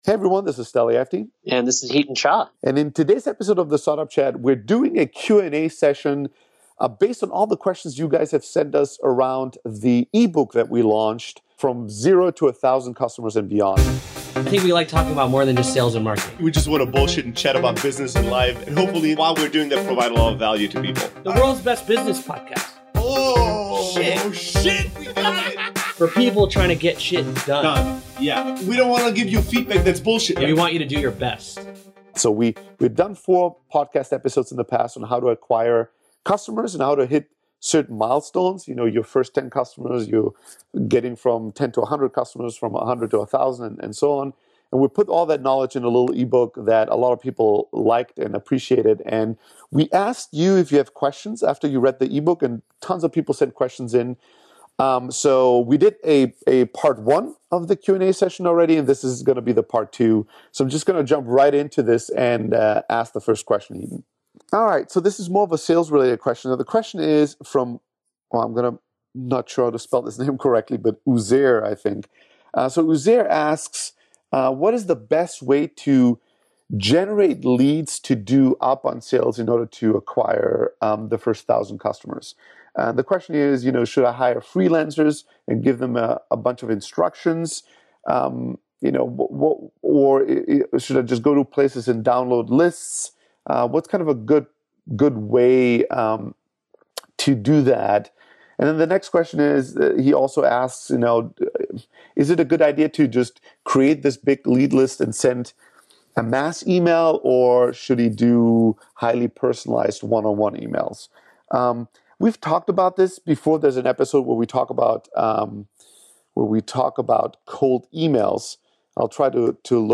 180: B2B Customer Acquisition Q&A (Part 2)